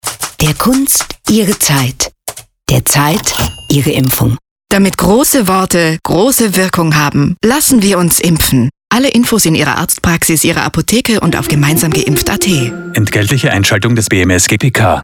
Hörfunk Spots